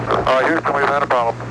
A famous line from the movie Apollo 13.